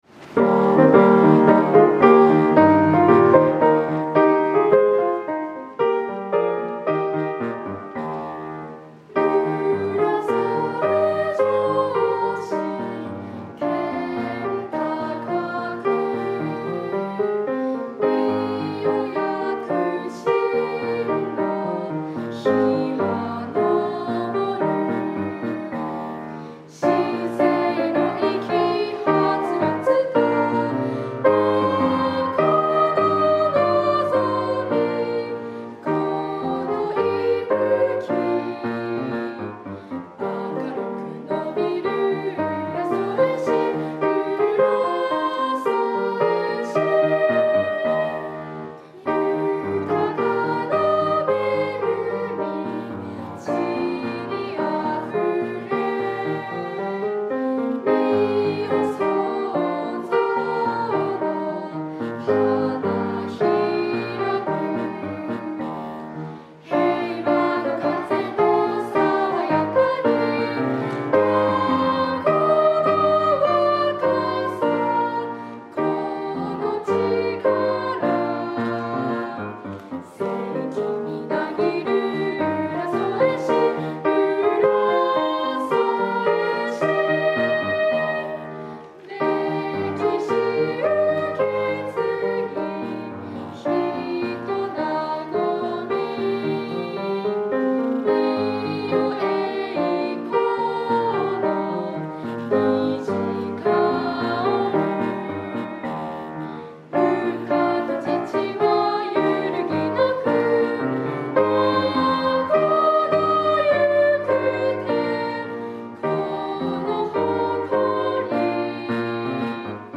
編曲　寺岡　真三
浦添市歌（浦添少年少女合唱団）.mp3[MP3：4MB]
浦添少年少女合唱団の方々に歌っていただいた浦添市歌の音源をアップしました！